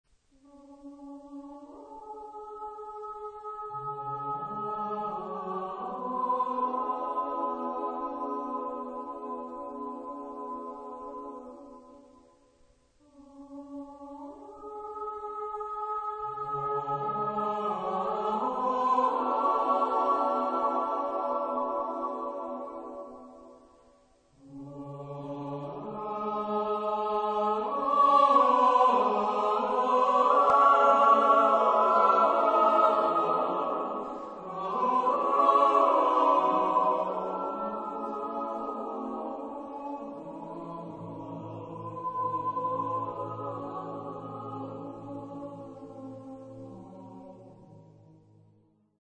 Genre-Style-Forme : Profane ; Lyrique ; Chœur
Type de choeur : SATB  (4 voix mixtes )
Tonalité : do mineur